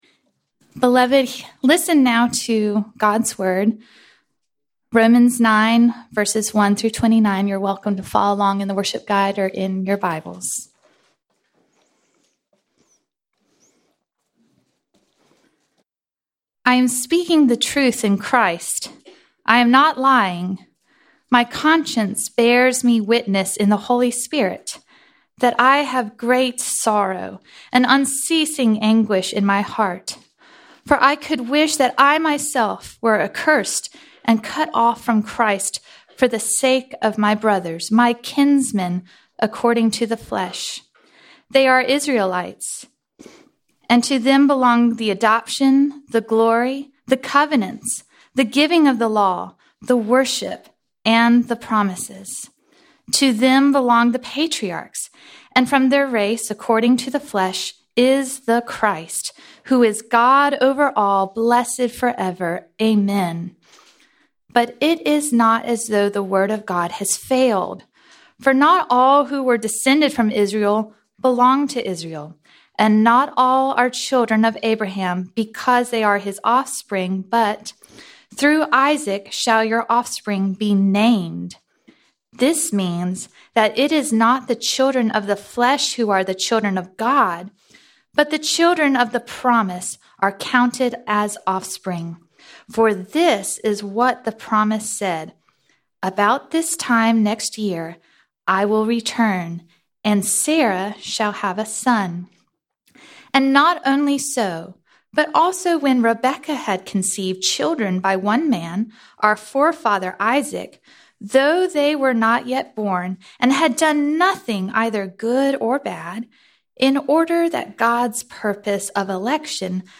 Format: Sermons